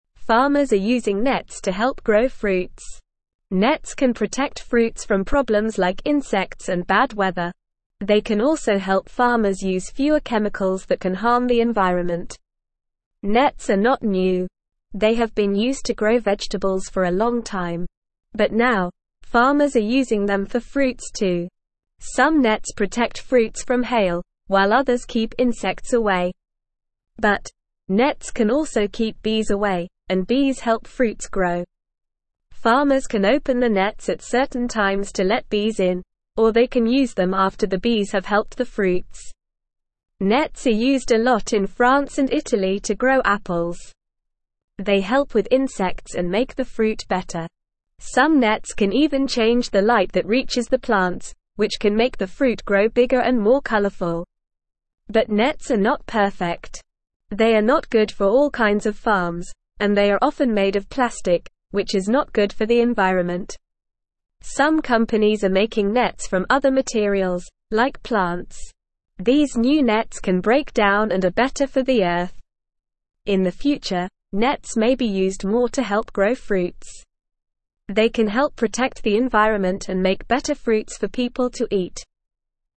English-Newsroom-Beginner-NORMAL-Reading-Farmers-Use-Nets-for-Growing-Fruits.mp3